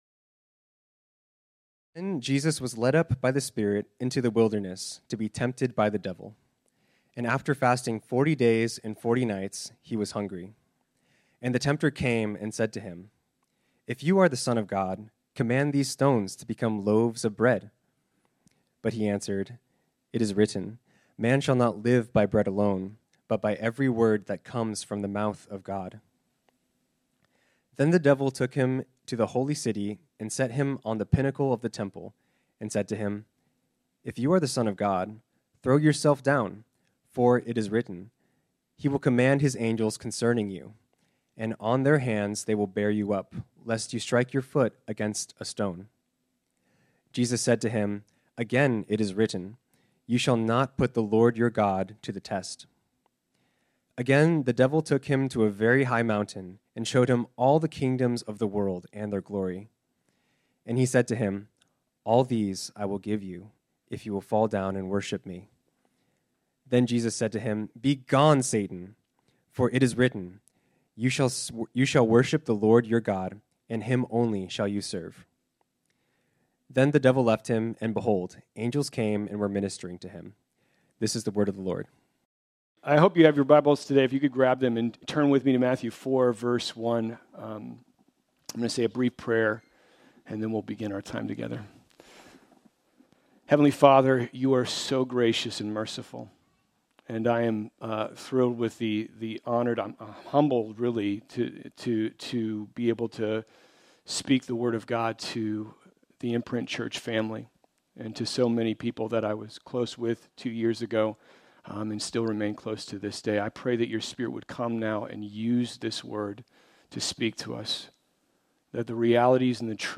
This sermon was originally preached on Sunday, January 14, 2024.